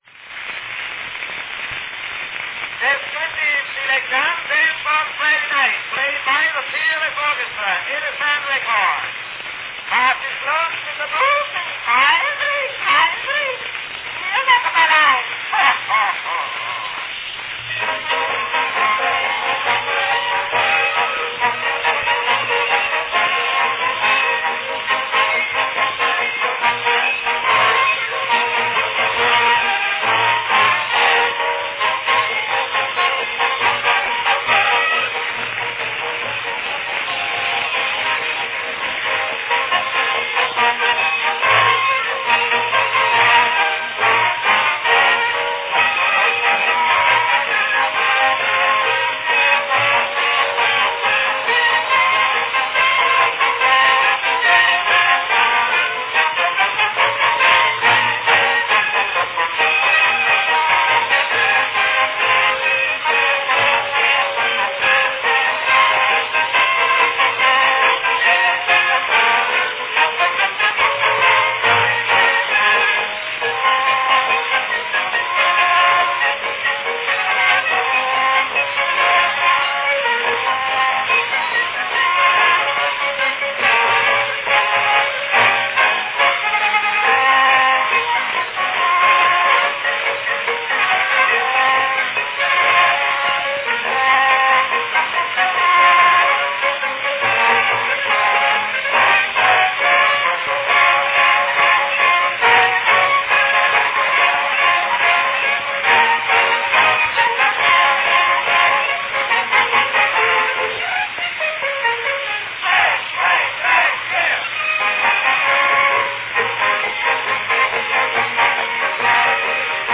the joyous sound
Category Descriptive selection